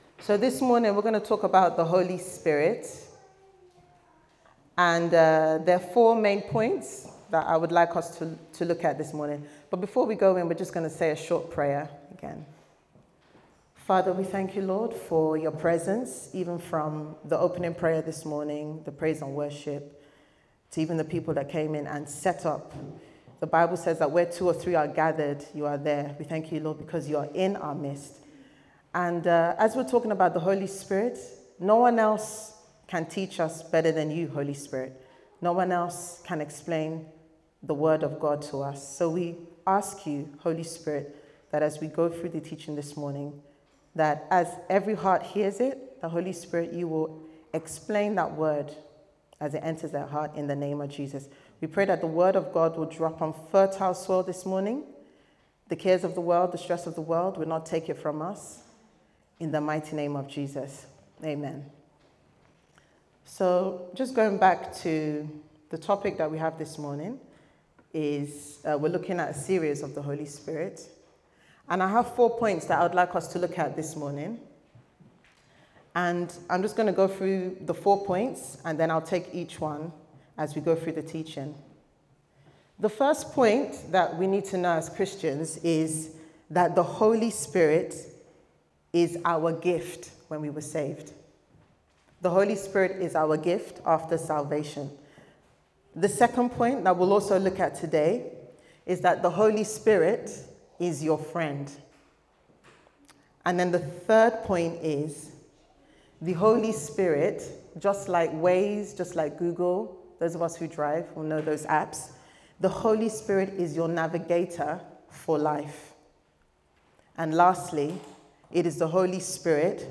The Holy Spirit & Your Purpose Service Type: Sunday Service Sermon « The Holy Spirit & Your Purpose